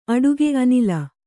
♪ aḍuge anila